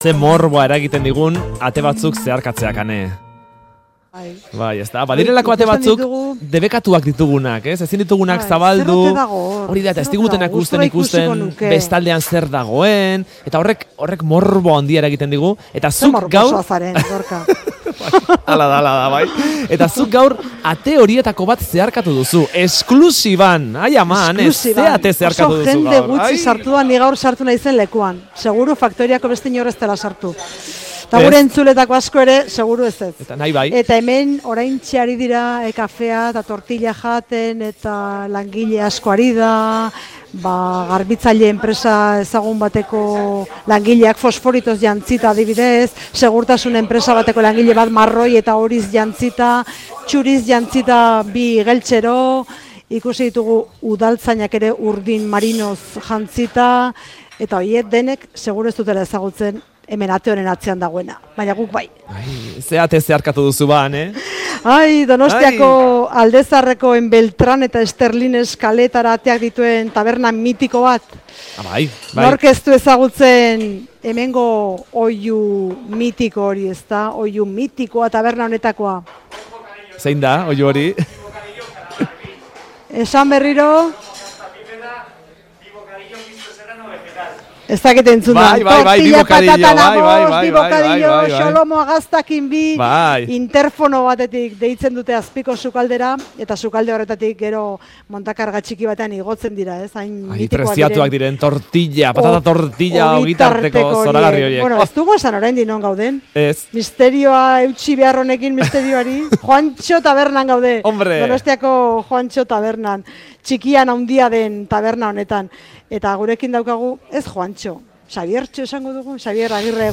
Audioa: Donostiako Juantxo tabernako sukaldean, patata tortilla ikustera